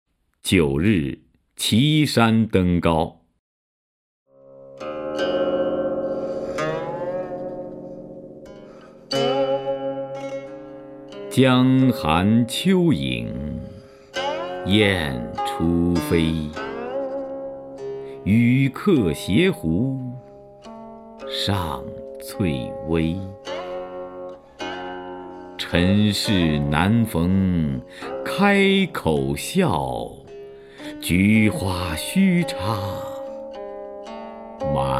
徐涛朗诵：《九日齐山登高》(（唐）杜牧)
名家朗诵欣赏 徐涛 目录